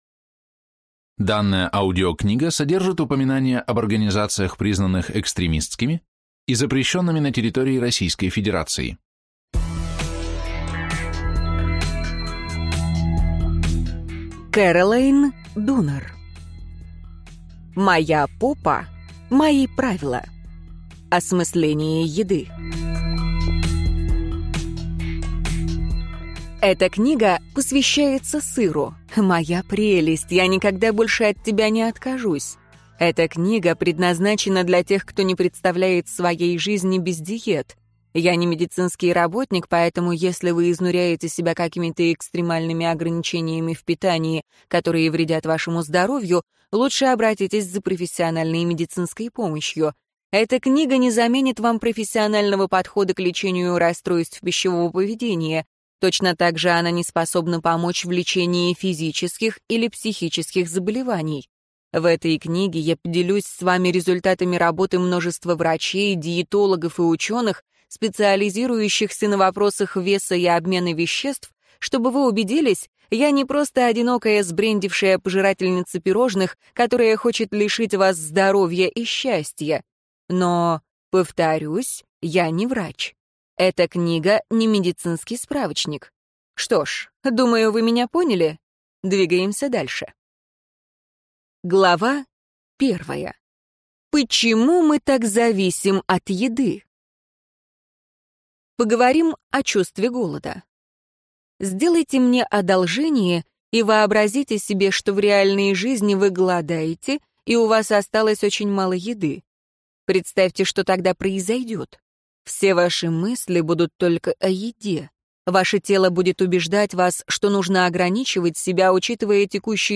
Аудиокнига Моя попа – мои правила. Осмысление еды | Библиотека аудиокниг